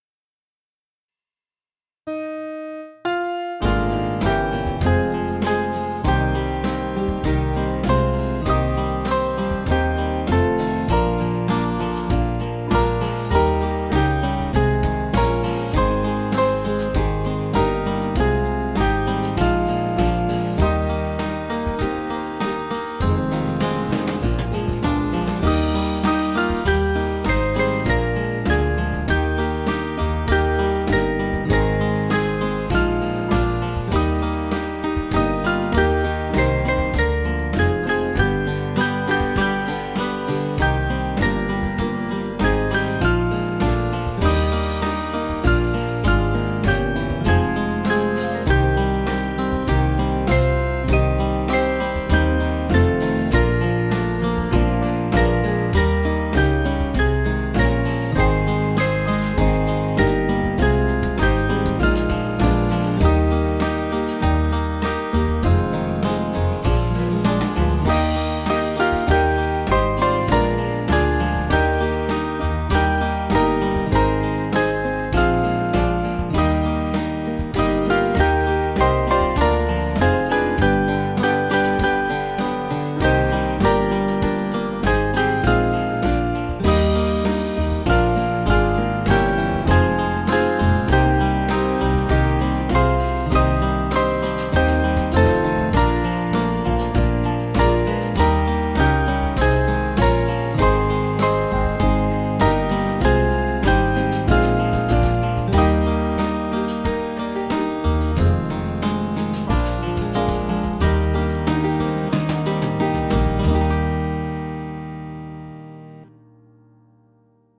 Small Band
(Sing twice)   552.2kb